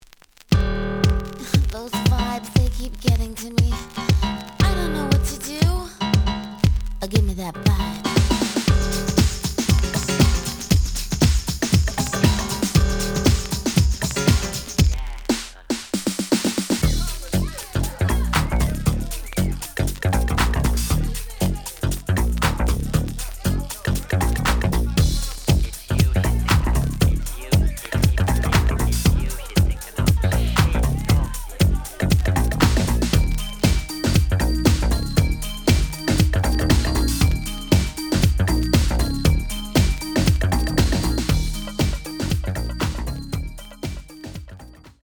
The audio sample is recorded from the actual item.
●Genre: House / Techno
Looks good, but slight noise on both sides.)